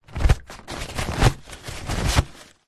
Бумажный полотенцедиспенсер настенный для больниц и клиник возьмите три полотенца